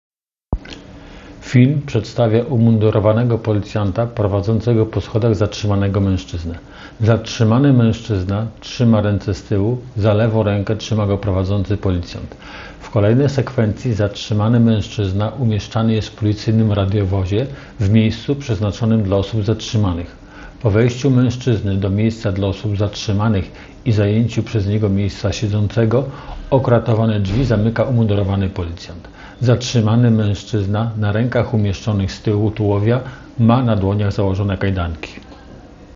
Nagranie audio Audio deskrypcja do filmu zatrzymany mężczyzna